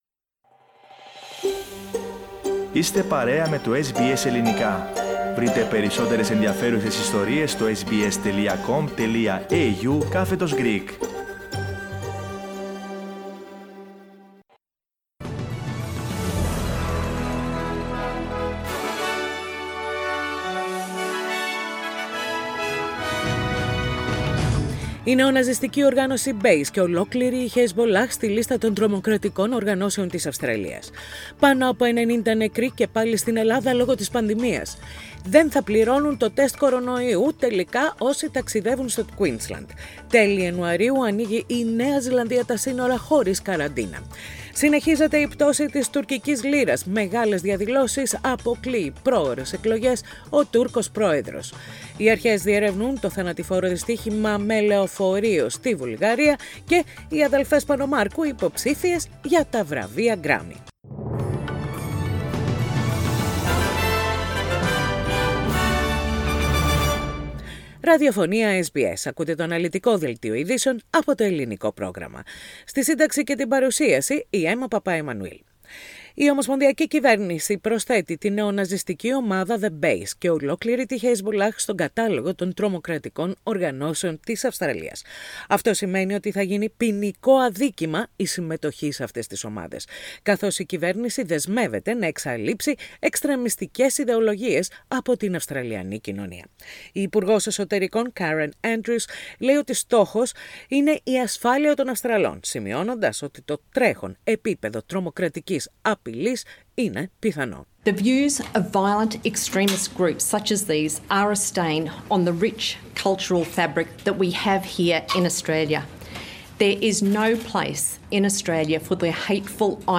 News in Greek - Wednesday 24.11.21